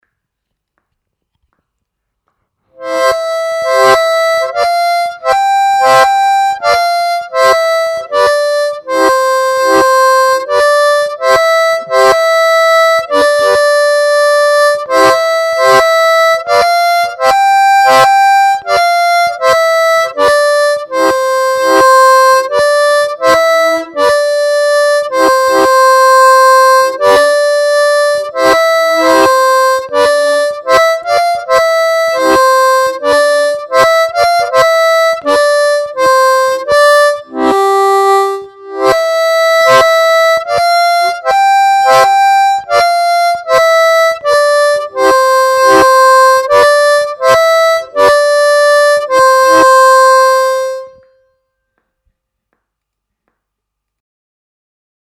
Техника блокировки языком: одиночные ноты
Научиться использовать эту технику в контексте игры композиций.